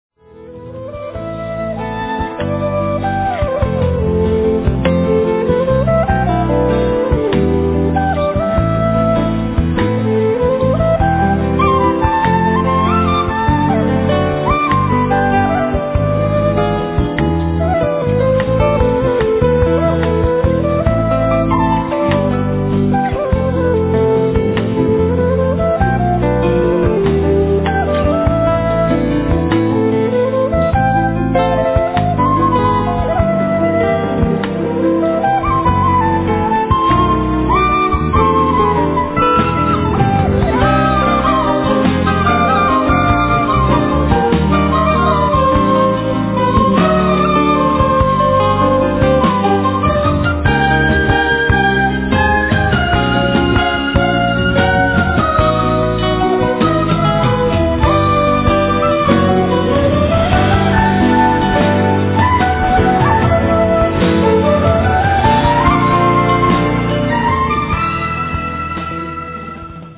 piano, organ, keyboards, percussions, bandoneon, drums
guitar
quena, sikus, udu, moczeno
drums
bass
bandoneon
percussions
美麗なピアノの音に、爽やかで時には哀愁深いケーナが響き渡る、壮大なサウンド。